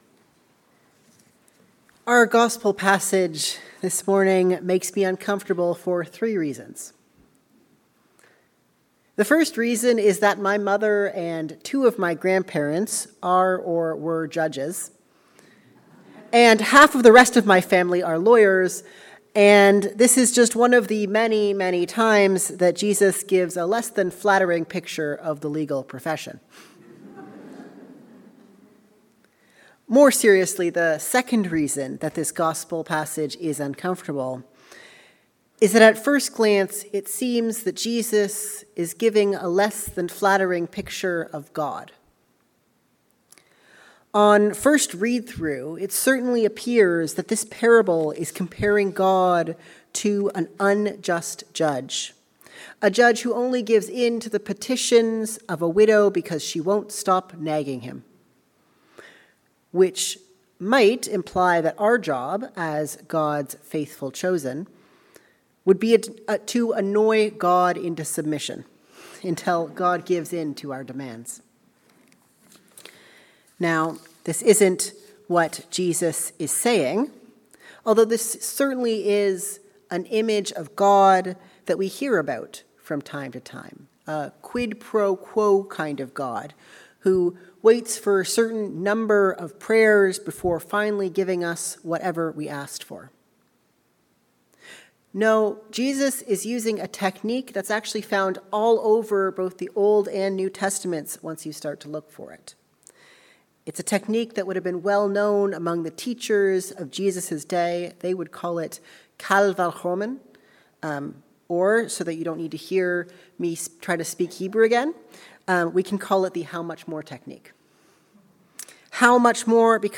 A sermon on the parable of the widow and the unjust judge.